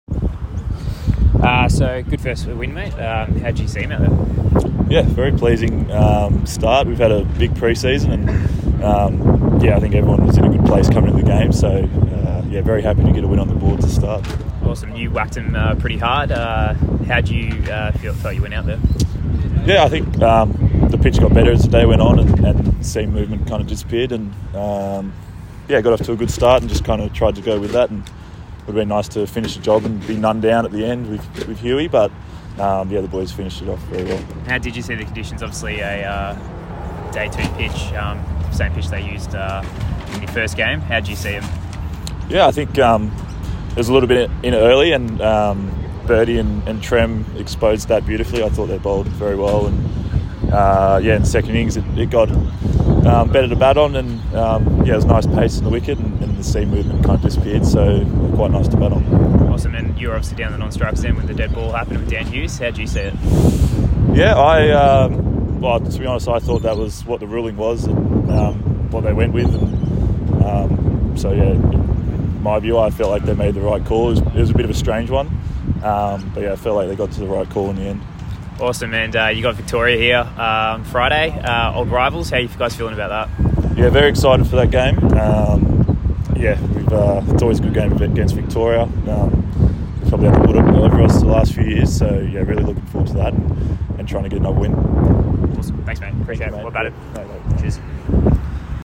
speaks after the match New South Wales won by 6 wickets.